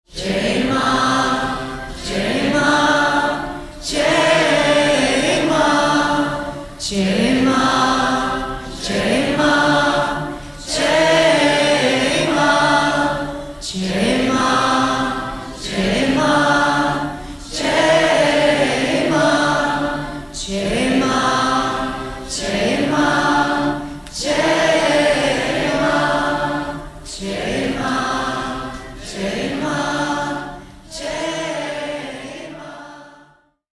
All these voices are so beautiful. What a choir.